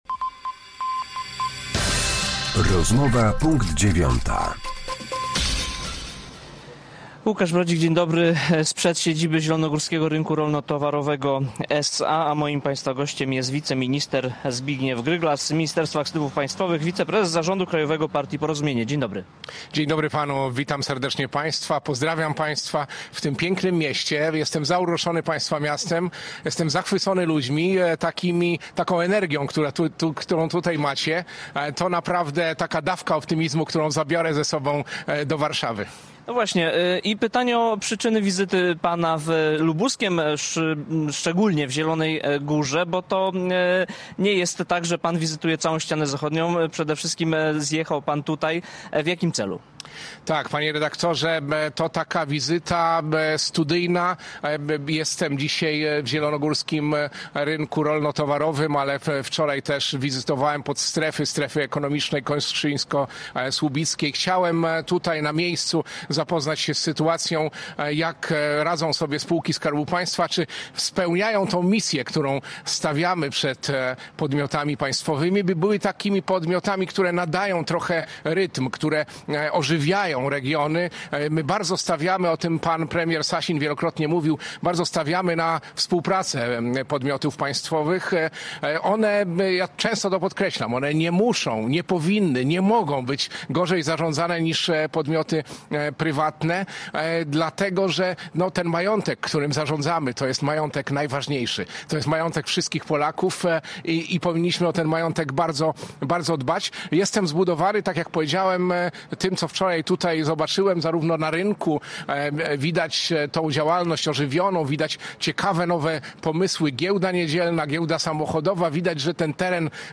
Z Wiceministrem Aktywów Państwowych, wiceprezesem zarządu krajowego partii Porozumienie rozmawia